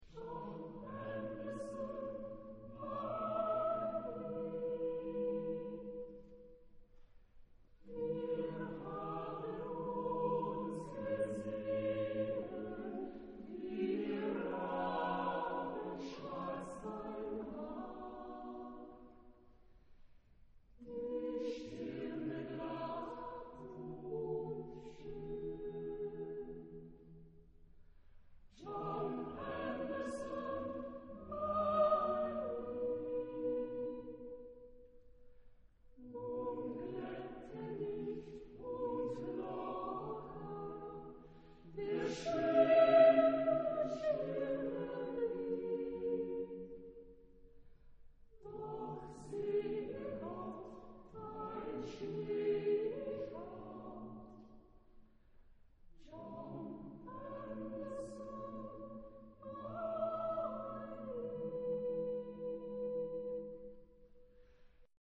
Genre-Style-Form: Secular ; Romantic ; Choral song ; Romance
Mood of the piece: slow
Type of Choir: SATB  (4 mixed voices )
Tonality: A major
Discographic ref. : Internationaler Kammerchor Wettbewerb Marktoberdorf